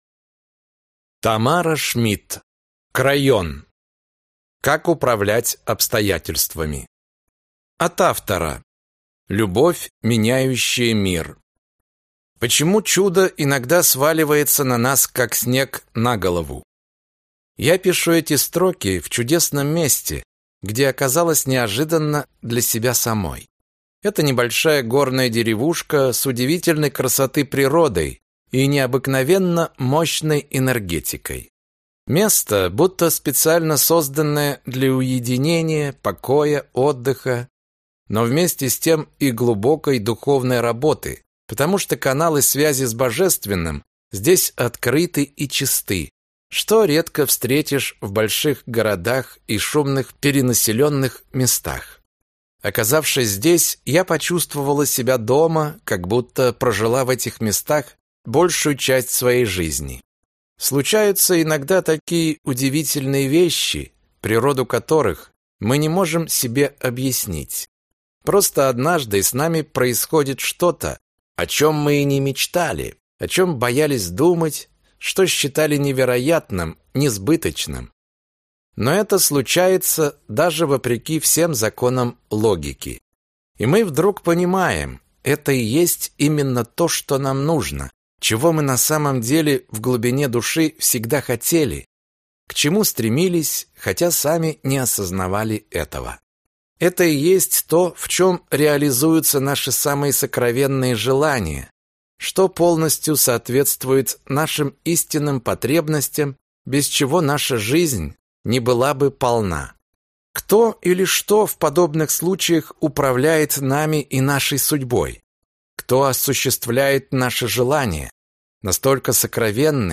Аудиокнига Крайон. Как управлять обстоятельствами | Библиотека аудиокниг